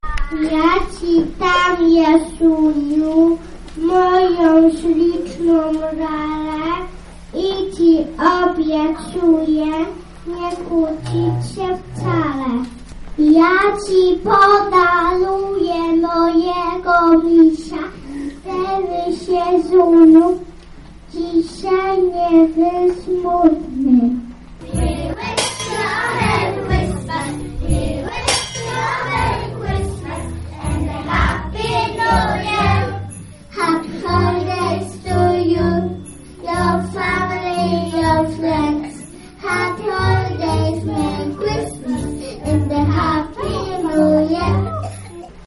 W zaprezentowanych dziś przez przedszkolaki jasełkach prócz małego Jezuska, Maryi i Józefa pojawiły się aniołki oraz przedstawiciele grup etnicznych jak Indianie i Eskimosi.
1-przedstawienie.mp3